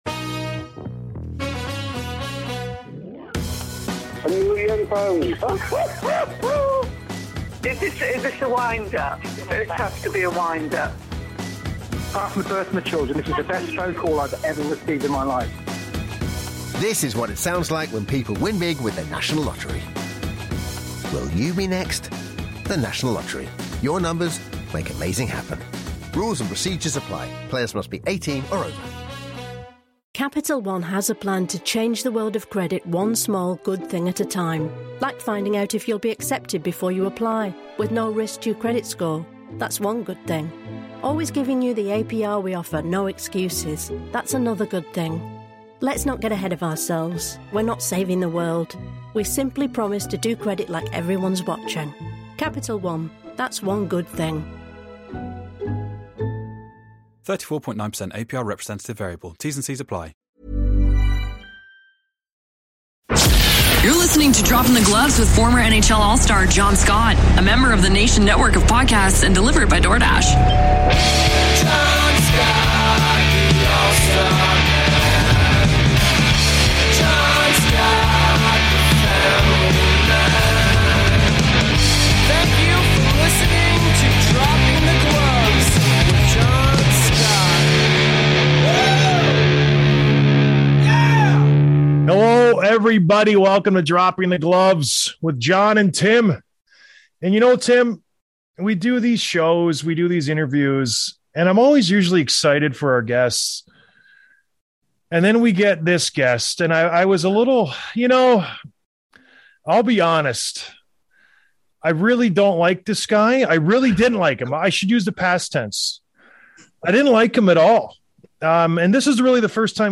Interview with Radko Gudas, Florida Panthers